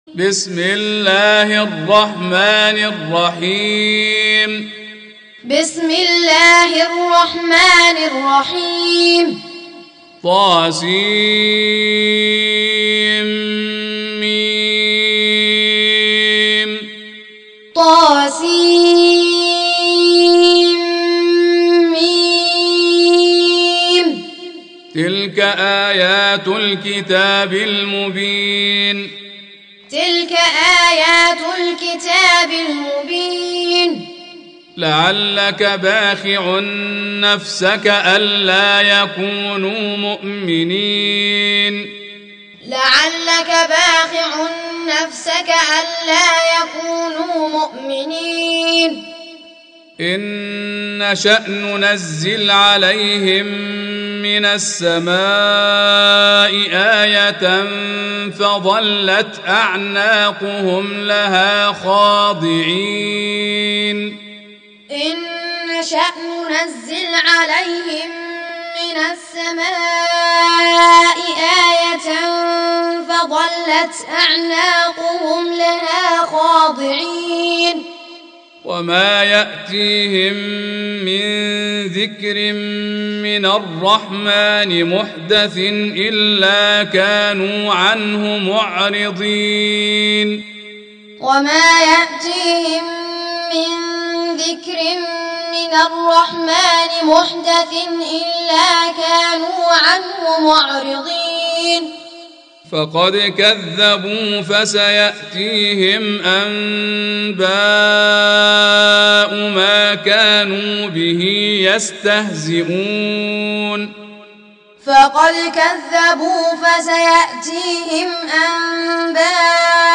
Surah Repeating تكرار السورة Download Surah حمّل السورة Reciting Muallamah Tutorial Audio for 26. Surah Ash-Shu'ar�' سورة الشعراء N.B *Surah Includes Al-Basmalah Reciters Sequents تتابع التلاوات Reciters Repeats تكرار التلاوات